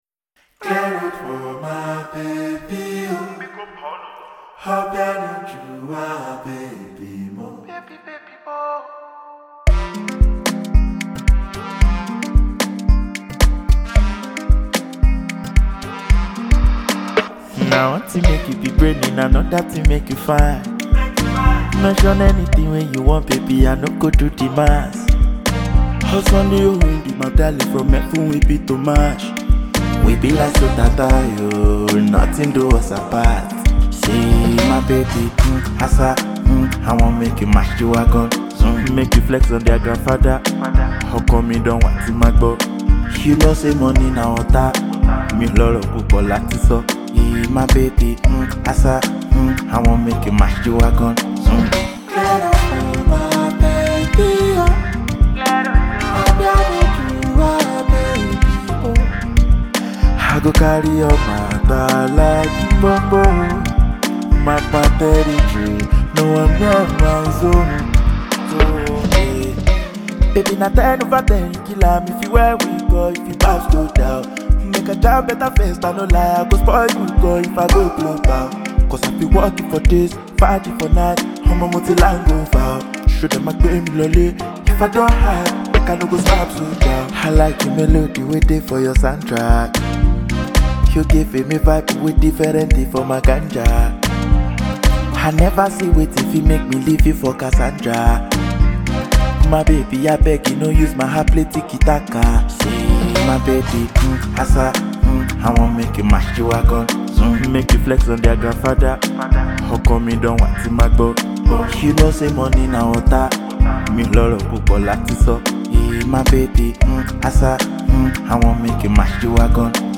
Nigerian vocal innovator